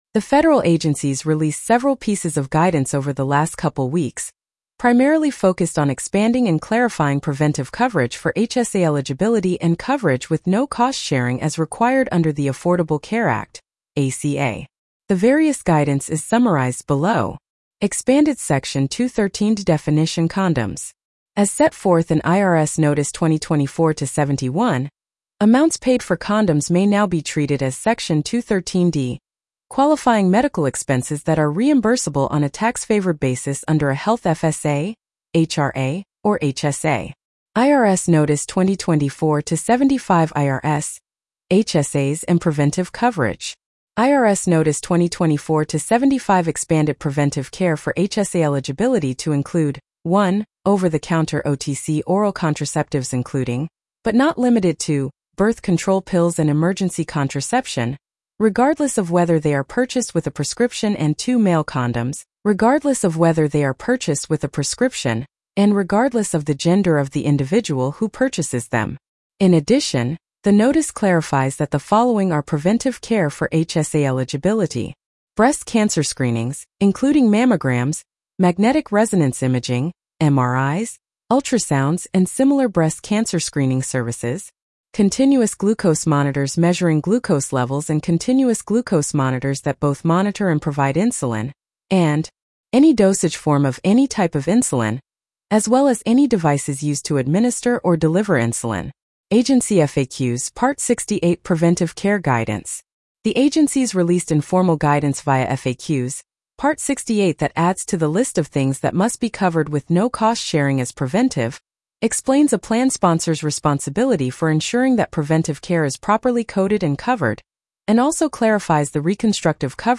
Regulatory Remix Blog Narration.mp3